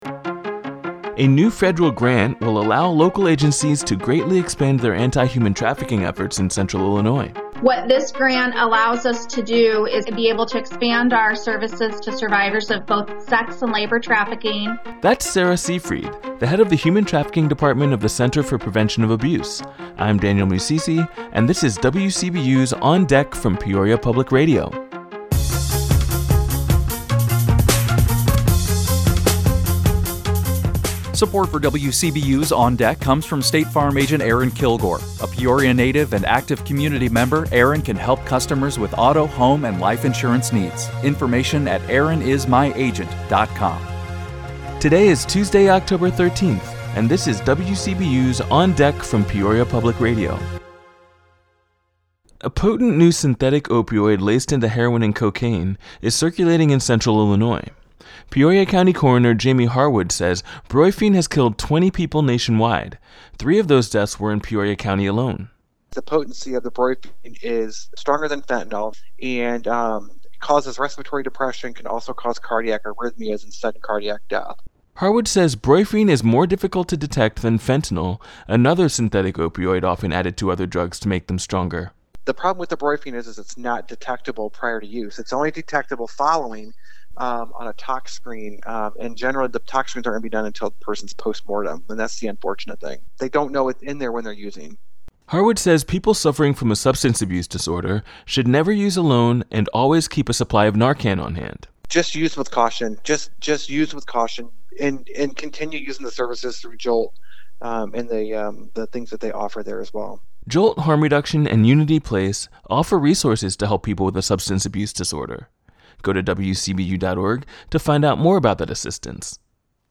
Our top story is about how a potent synthetic opioid laced into heroin and cocaine is circulating in Central Illinois. You'll hear from Peoria County Coroner Jamie Harwood who says brorphine has killed 20 people nationwide and three of those deaths were in Peoria County. You'll also hear how a $75,000 federal grant will expand anti-trafficking efforts in 46 Central Illinois counties.